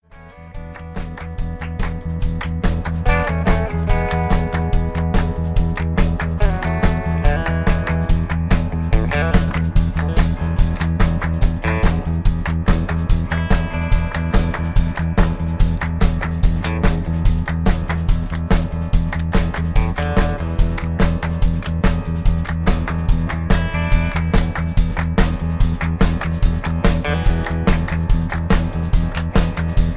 karaoke, mp3 backing tracks
rock and roll, r and b, rock, backing tracks